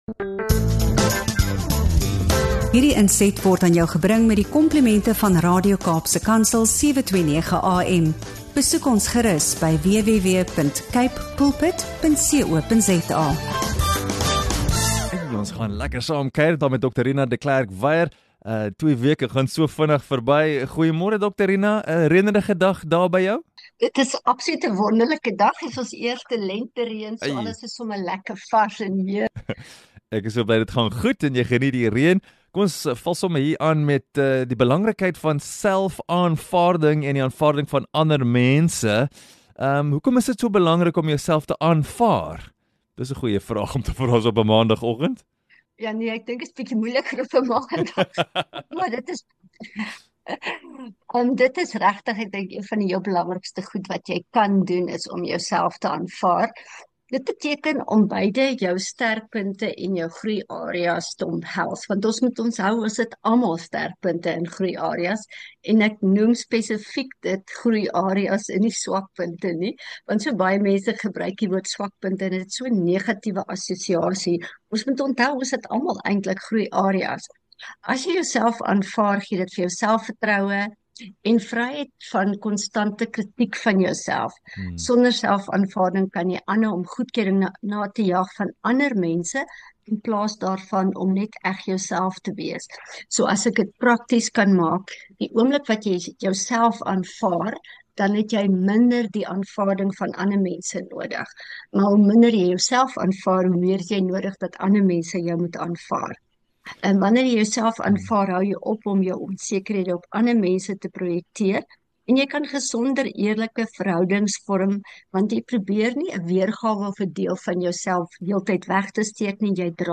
Hoe stop jy die eindelose kritiek in jou kop en leer jy om jouself regtig te aanvaar? In hierdie insiggewende gesprek